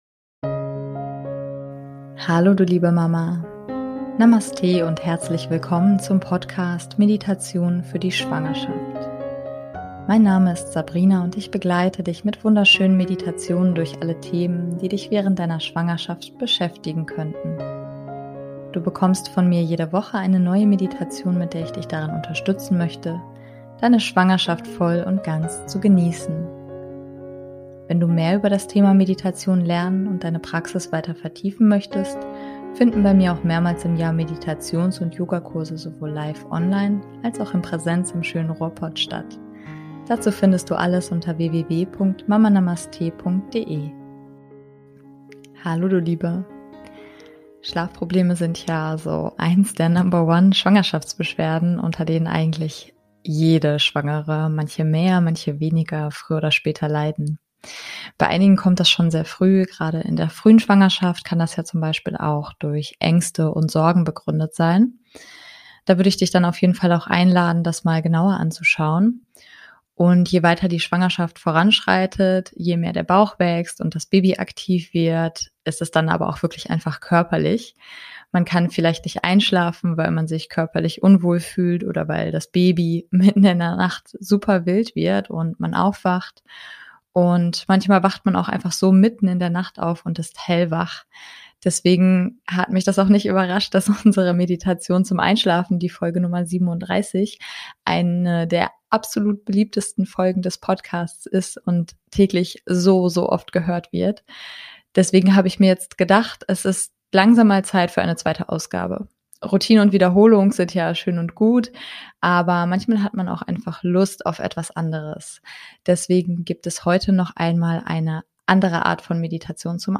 Die Meditation endet mit der Musik die einfach in bisschen ausläuft, wenn du dann hoffentlich bereits schläfst.